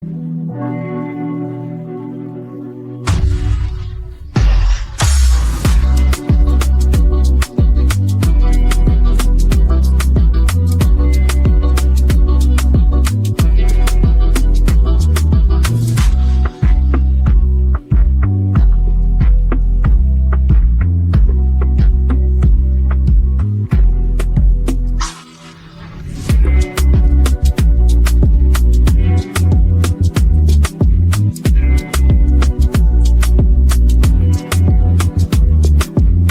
Song TypePunjabi Pop